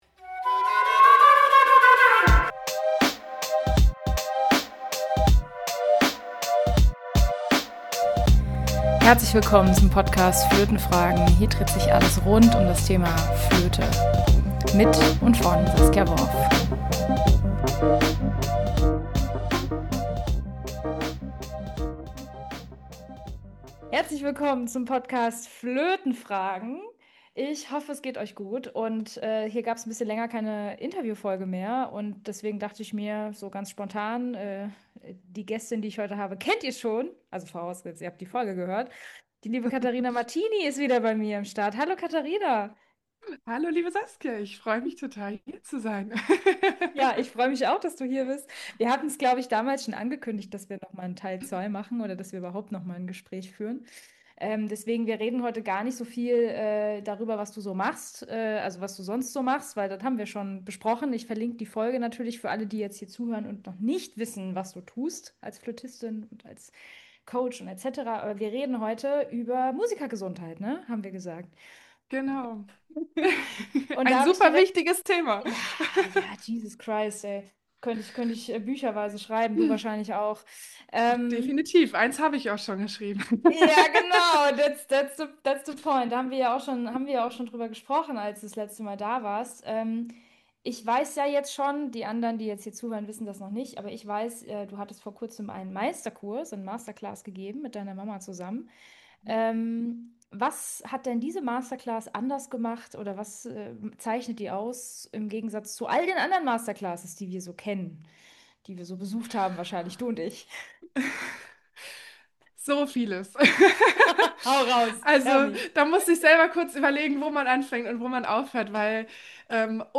Resonating Body Methode X Yoga for musicians – Talk